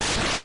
Slash.mp3